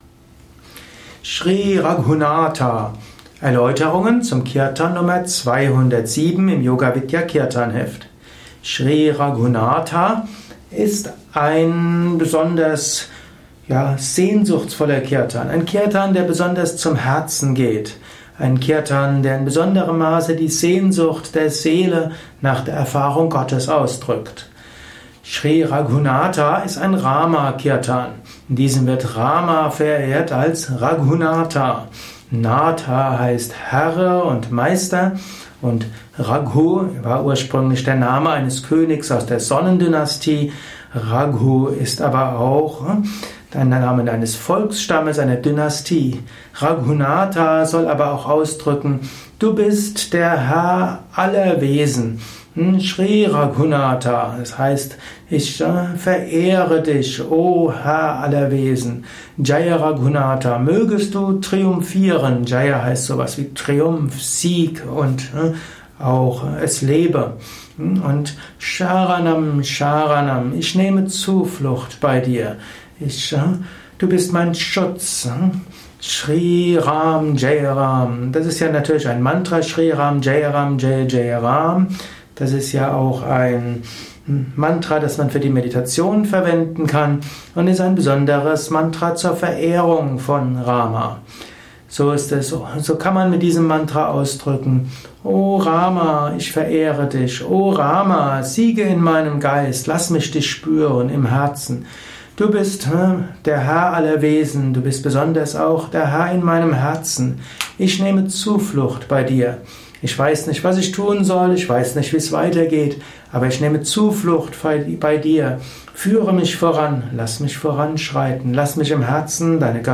Nr. 207 im Yoga Vidya Kirtanheft , Tonspur eines Kirtan